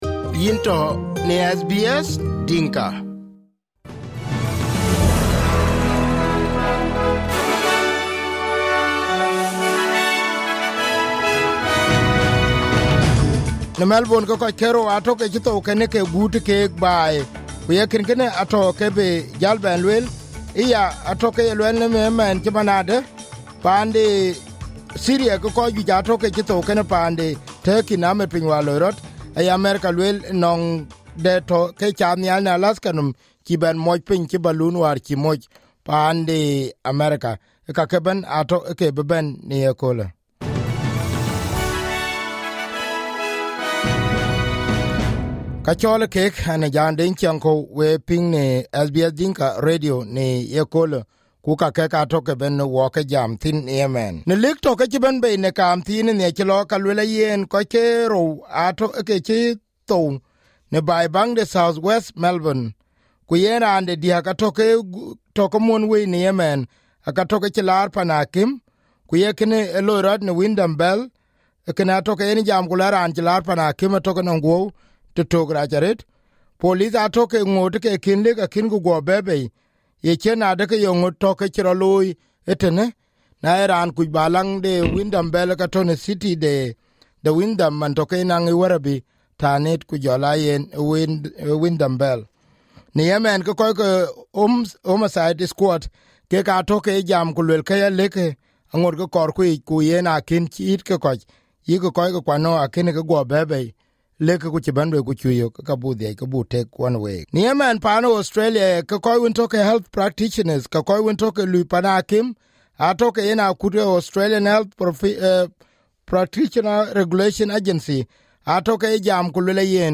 SBS Dinka Radio Podcast Source: SBS / SBS Dinka